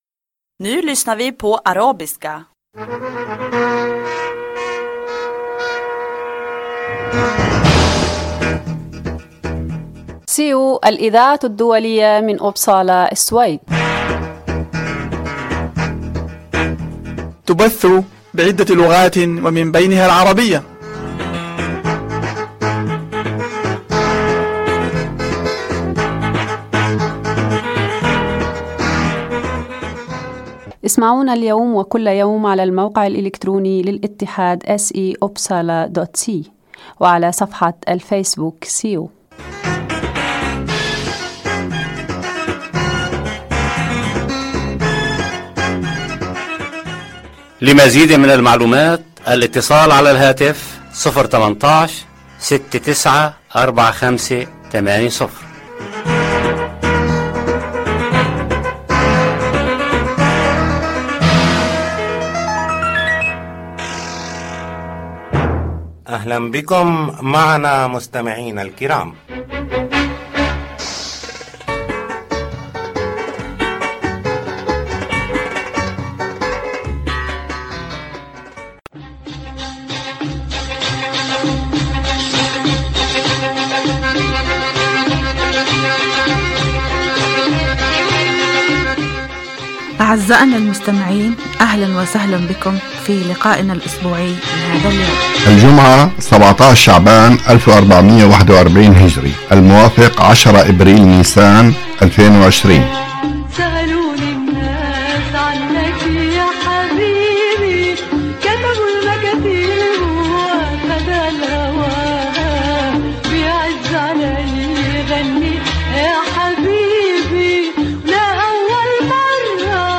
يتضمن البرنامج أخبار من مدينة إبسالا و السويد تهم المهاجرين و برامج ترفيهية و مفيدة أخرى. برنامج هذا الأسبوع يتضمن أخبار الاتحاد السيو، من أخبارنا المحلية و مقتطفات من الصحف العربية و أخبار متنوعة ومقابلات مع ضيوف السيو ومن الشعر و الموسيقى .